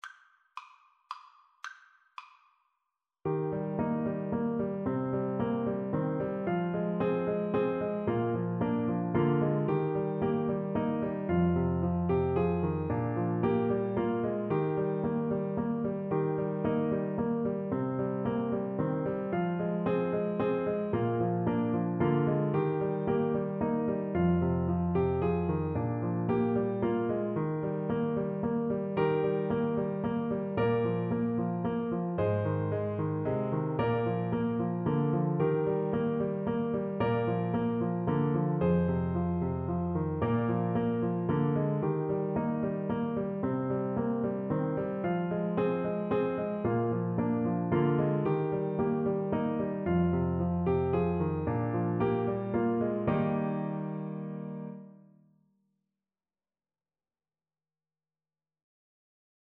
3/4 (View more 3/4 Music)
Eb5-G6
Moderately Fast ( = c. 112)